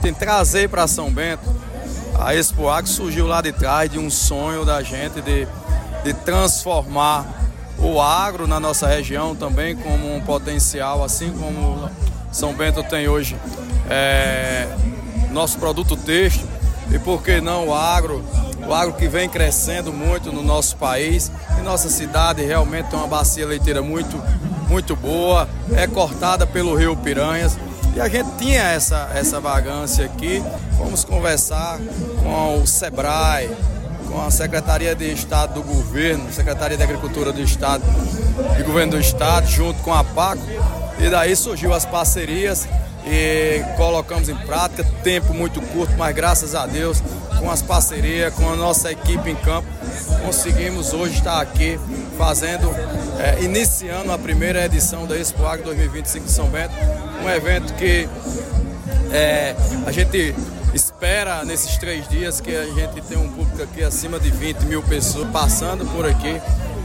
Ouça o áudio do Prefeito de São Bento Gerfeson Carnaúba: